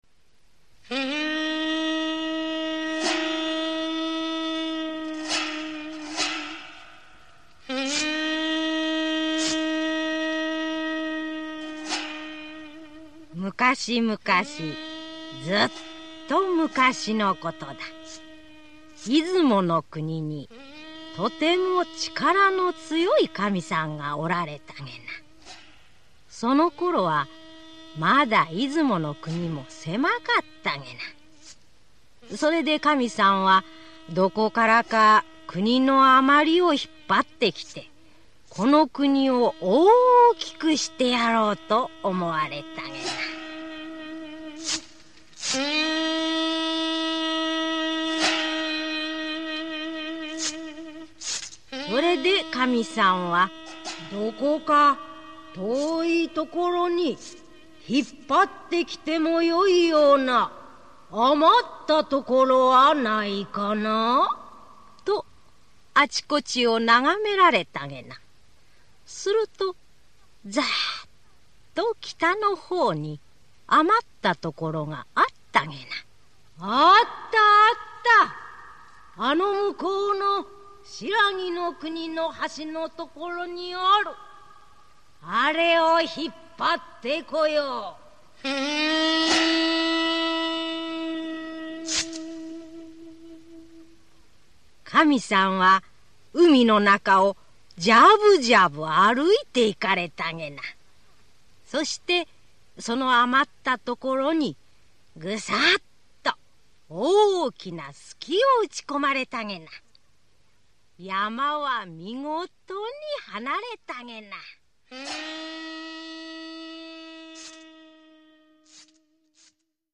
[オーディオブック] くに来くに来